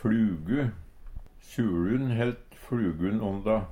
fLugu - Numedalsmål (en-US)
Hør på dette ordet Ordklasse: Substantiv hokjønn Attende til søk